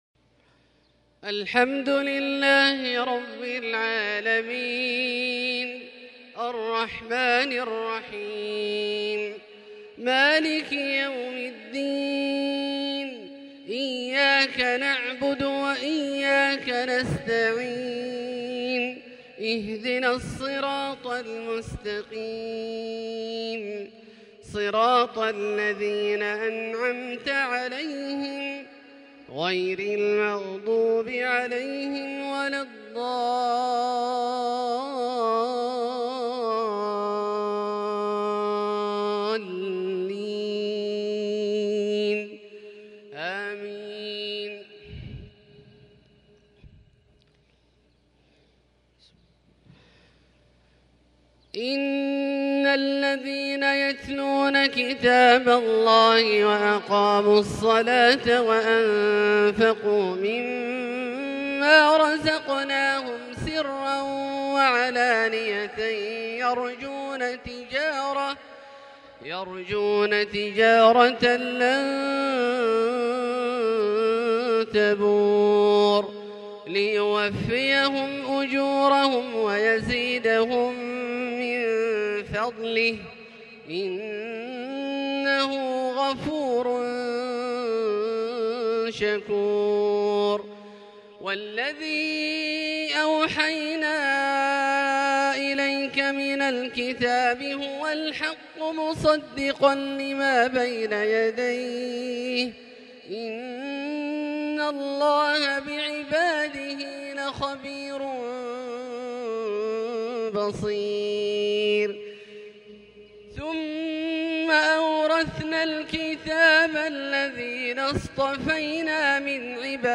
تلاوة بديعة من سُورة فاطر29-41 | عشاء ٢٧ شوال ١٤٤٢هـ > ١٤٤٢ هـ > الفروض - تلاوات عبدالله الجهني